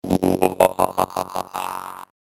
Tiếng Robot Cười (MP3)